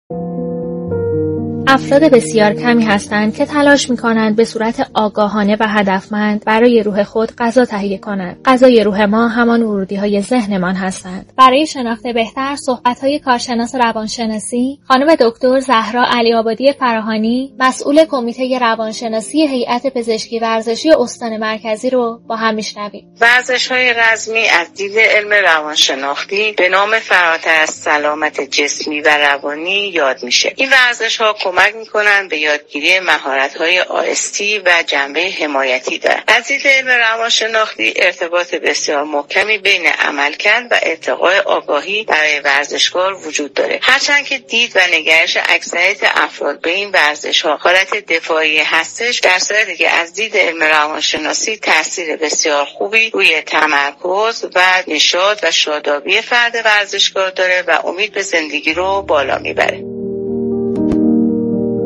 /گفت و گوی رادیویی/